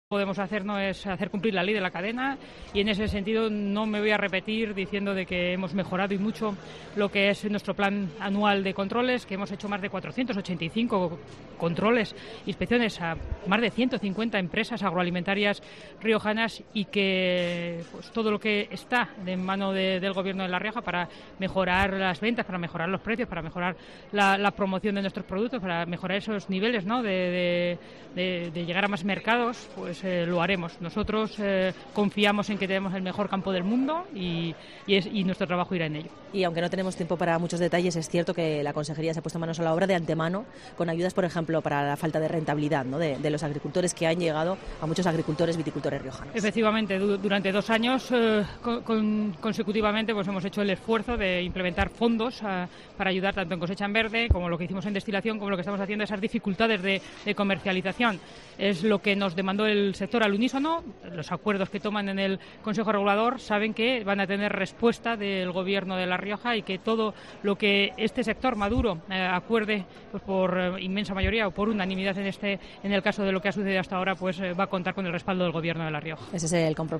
Noemí Manzanos, Consejera de Agricultura del Gobierno de La Rioja